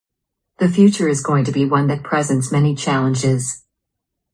Similarly, when AI is used to generate audio, it is usually unnaturally rapid and often applies inappropriate rhythm, word stress, and intonation, demonstrating that it cannot understand the overall meaning or context.
In one lesson, I share some examples of AI audio, where the incorrect word stress creates confusing sentences.
This word stress sounds like, ‘The future is going to be one that gifts many challenges.’